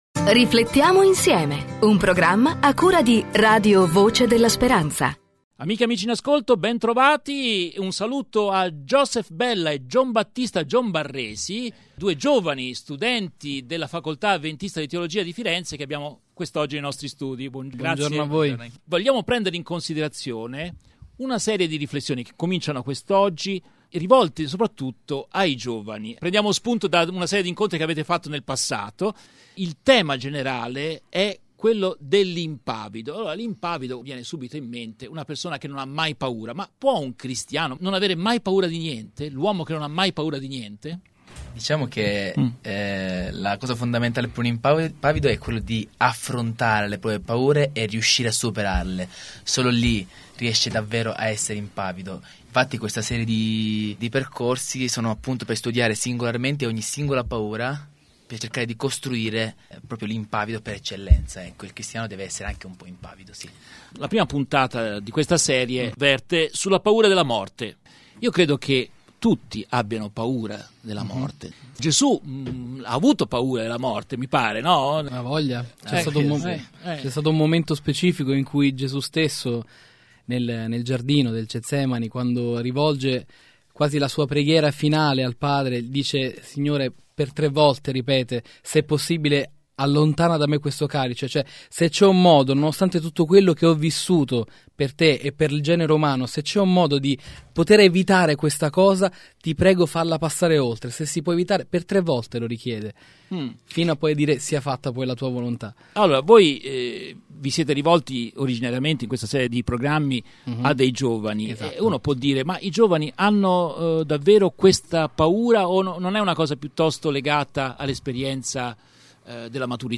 Radio Podcast